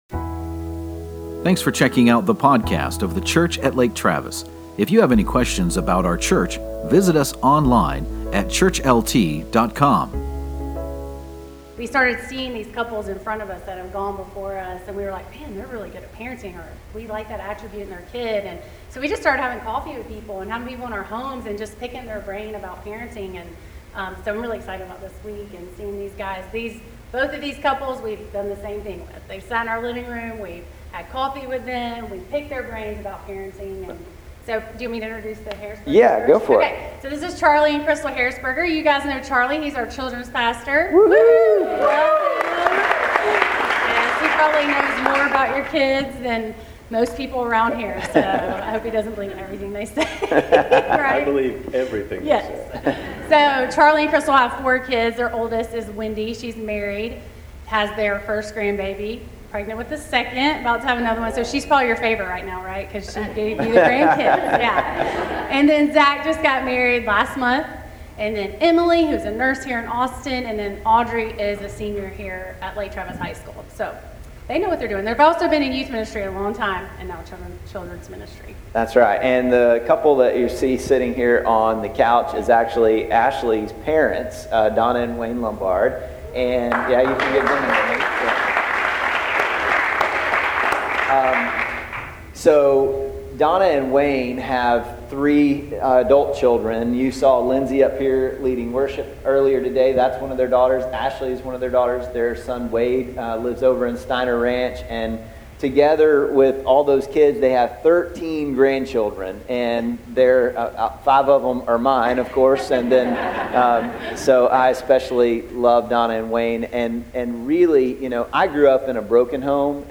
Success leaves clues. Find out more from our panelist of positive parents who raised some pretty incredible kids!